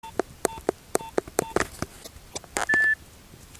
vyšší delší tón, signalizuje potvrzení.